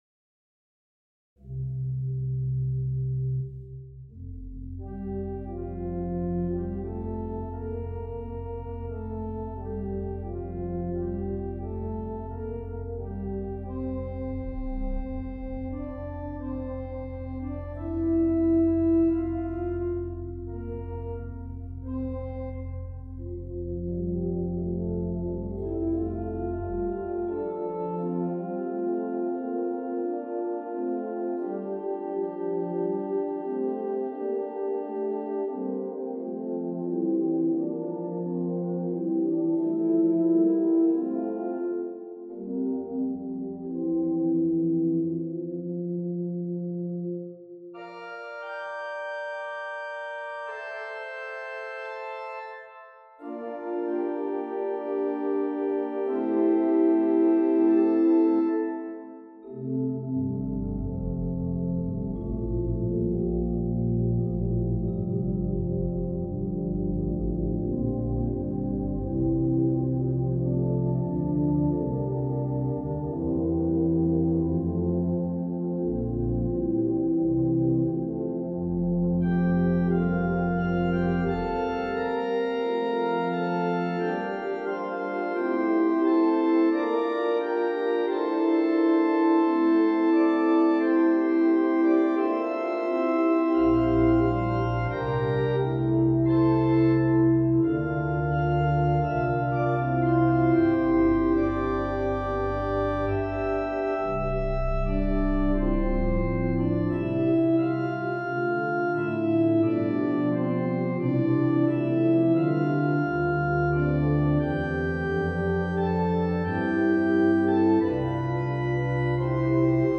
for organ The gentle eight-measure subject was promoted by a phrase in a hymn tune, and heard in quiet registrations and expected tonal regions.